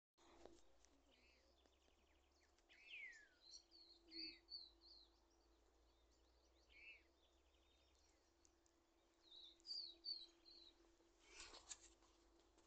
Suiriri Flycatcher (Suiriri suiriri)
Tres individuos
Province / Department: Santa Fe
Condition: Wild
Certainty: Observed, Recorded vocal